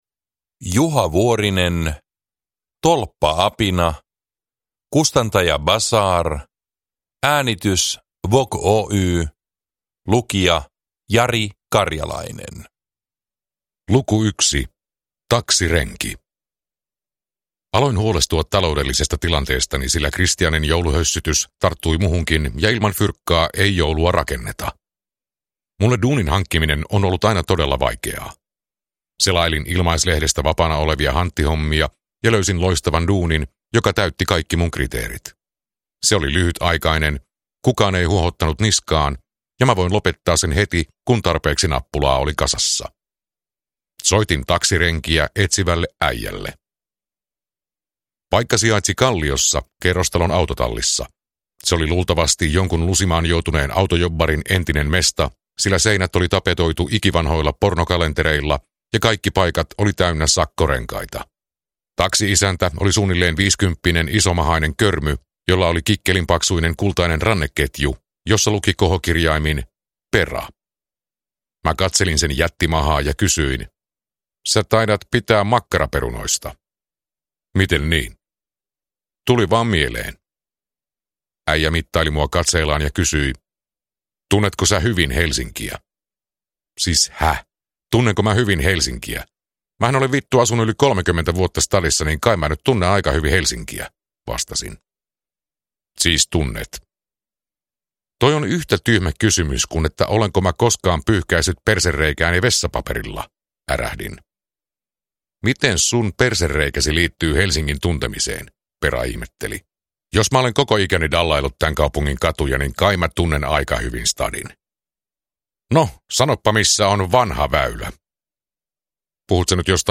Tolppa-apina – Ljudbok